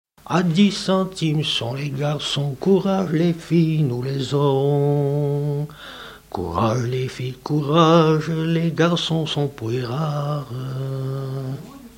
danse : ronde : grand'danse
Genre énumérative
Catégorie Pièce musicale inédite